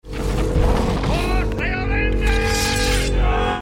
Just before riding out through the enemy for the last confrontation (Aragorn and Théoden and the men against the overwhelming Orcs, before Gandalf arrives with the reinforcements), a Rohir cries out the words: